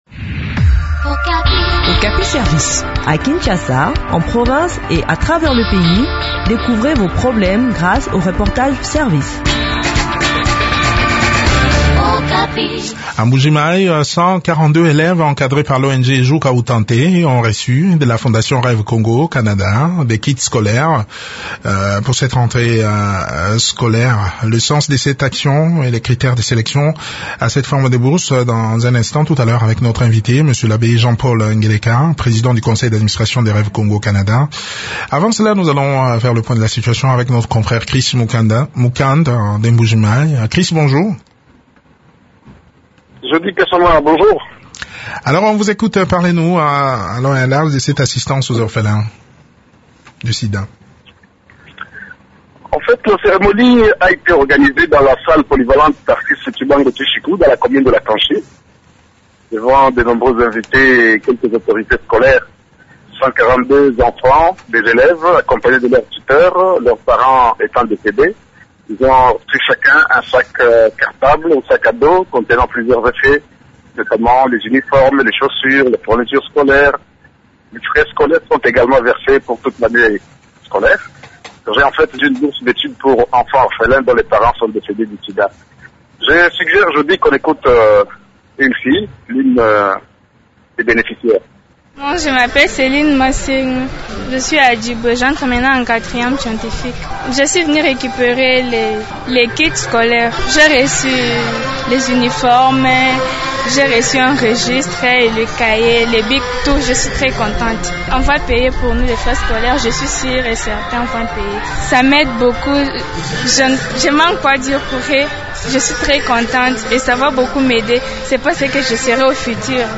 Le point du sujet dans cet entretien